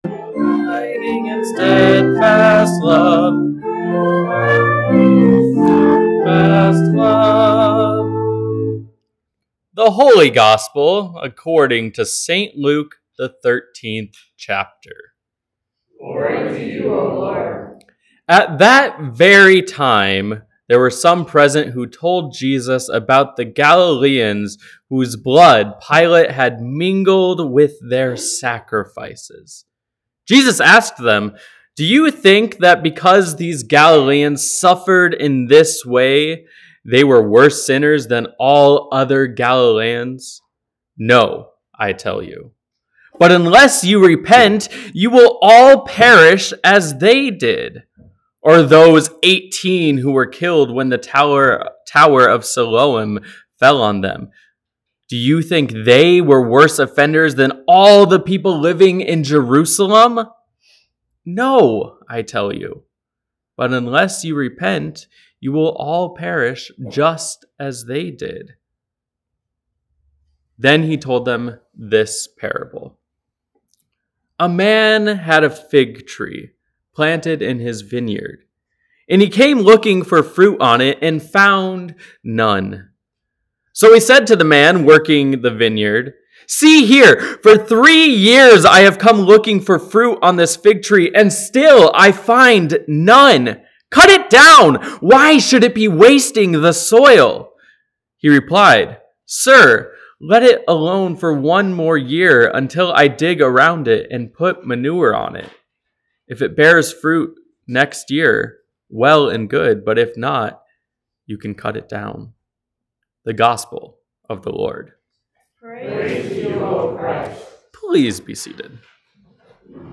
Sermons | Bethany Lutheran Church